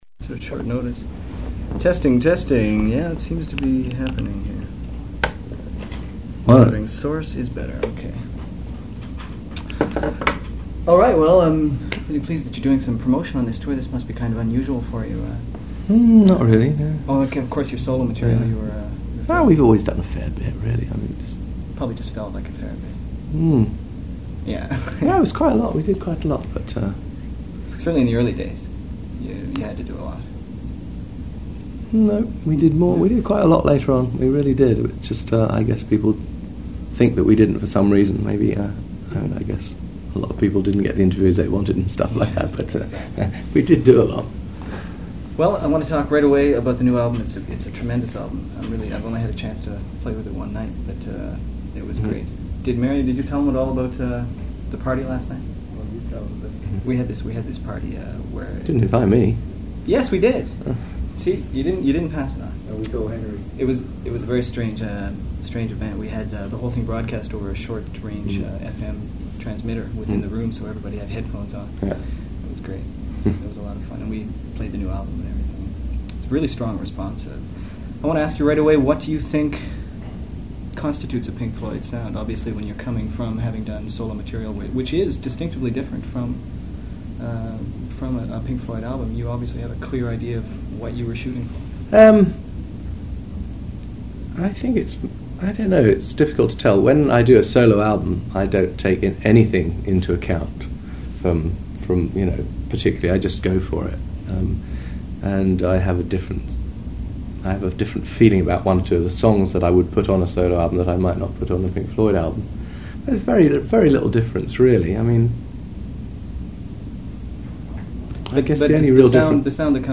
click here to download an unedited 75-min interview with Pink Floyd guitarist Dave Gilmour recorded in 1987 (mp3 format)